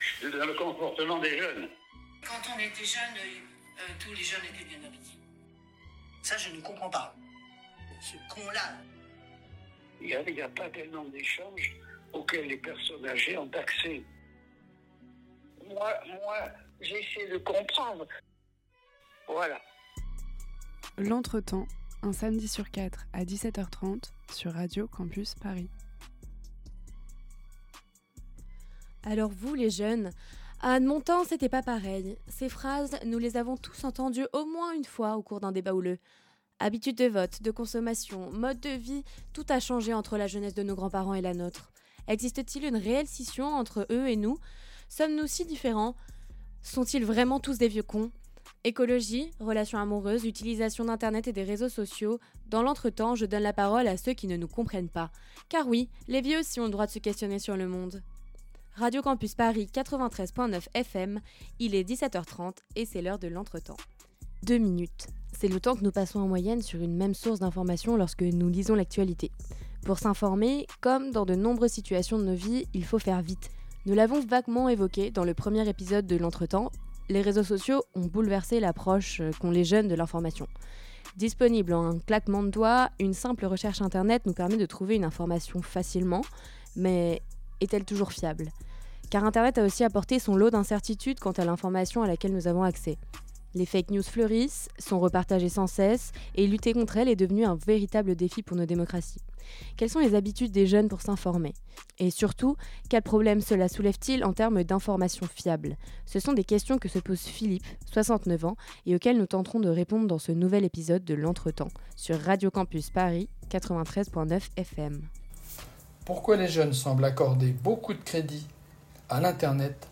Partager Type Entretien Société samedi 11 janvier 2025 Lire Pause Télécharger 93% des jeunes en France s'intéressent à l'actualité.